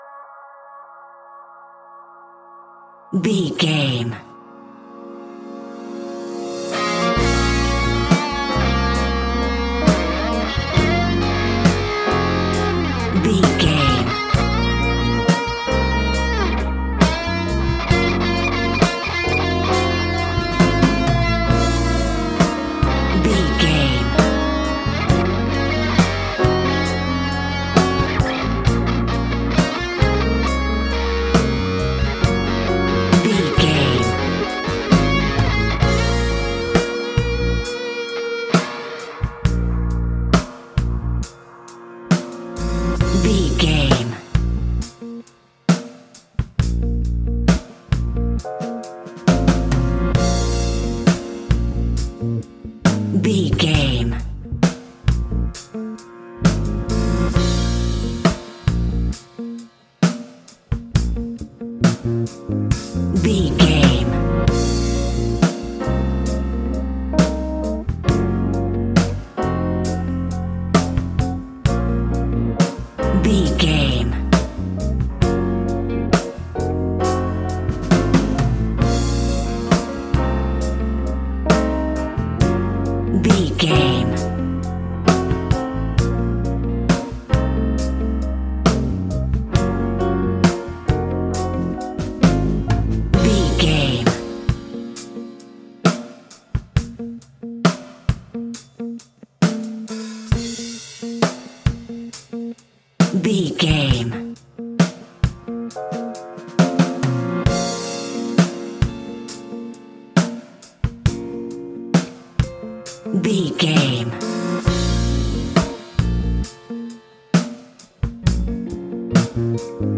Epic / Action
Fast paced
In-crescendo
Uplifting
Ionian/Major
A♯
hip hop